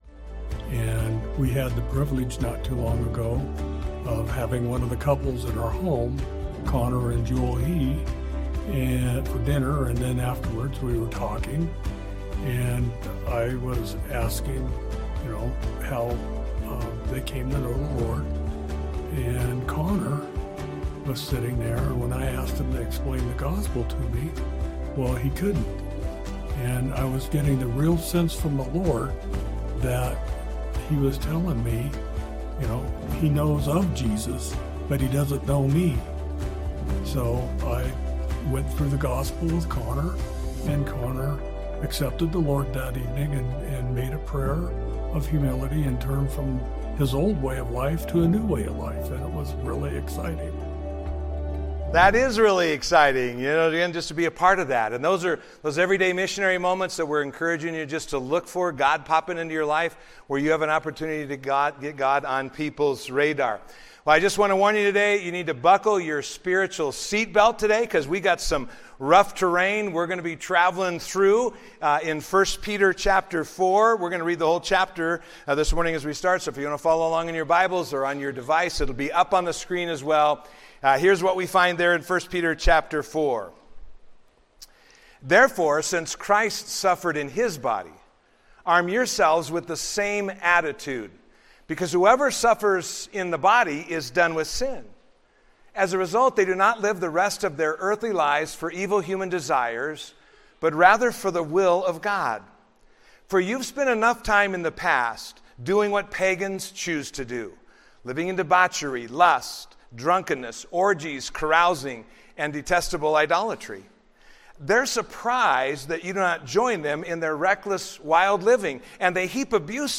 A message from the series "Rolling with the Rock."